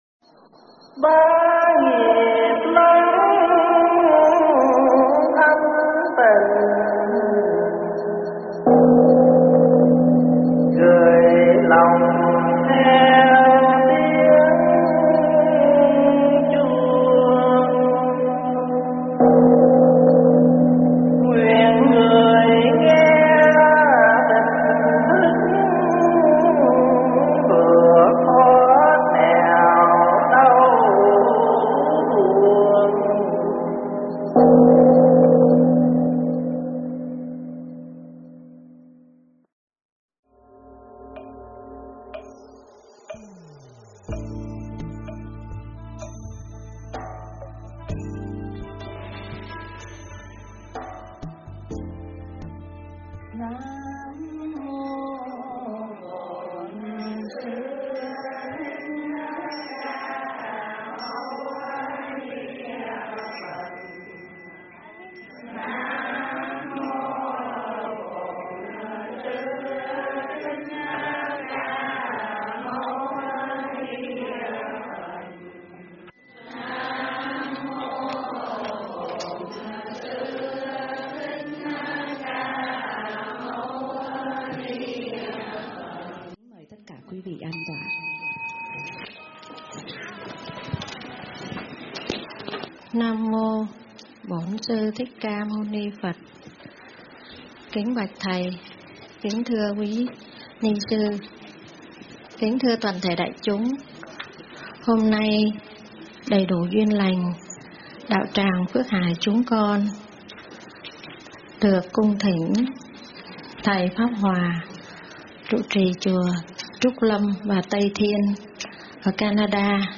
Mp3 thuyết pháp Sức Hấp Dẫn